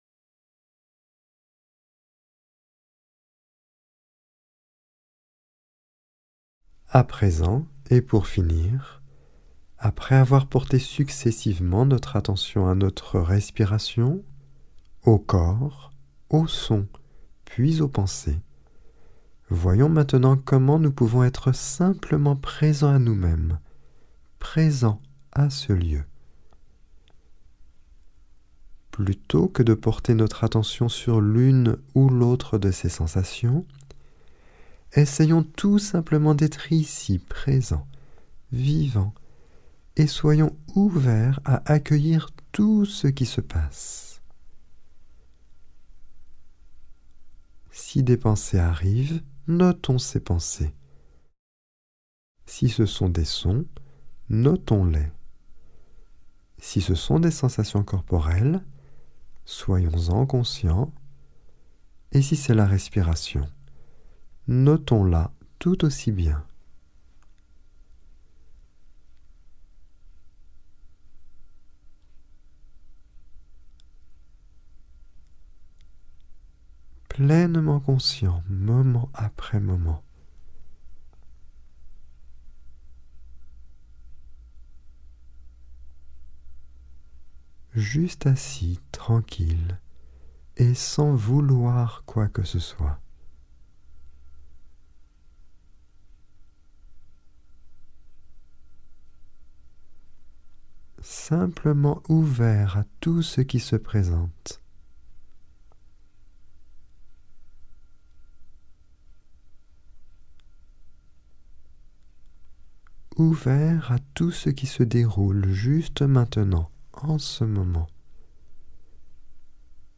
Genre : Meditative.
Etape 6 – Méditation assise complète 5